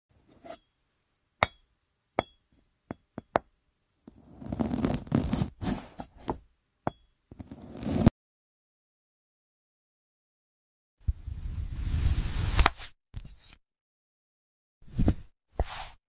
Ai Cutting Fails 🔪😔 Sound Effects Free Download